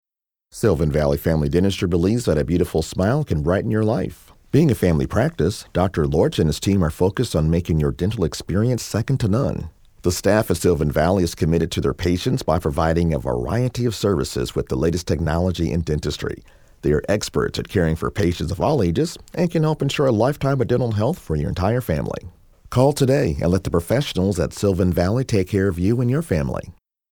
Local Advertisement